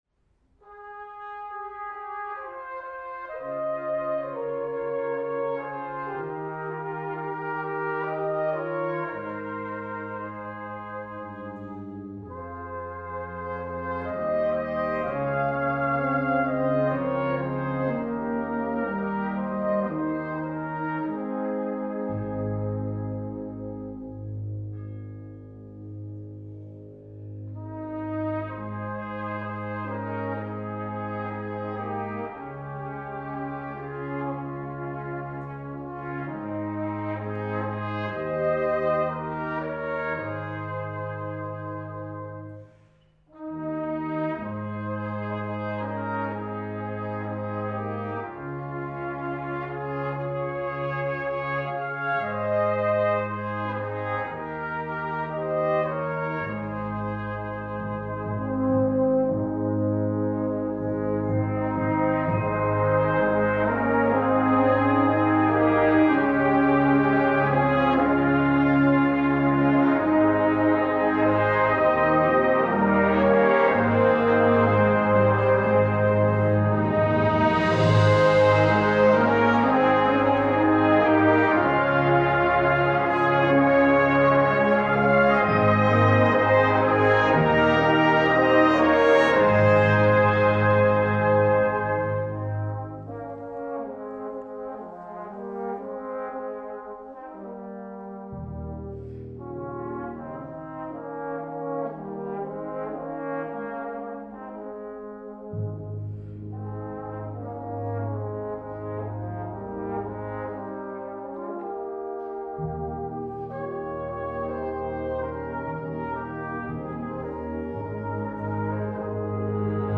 Gattung: Lied
Besetzung: Blasorchester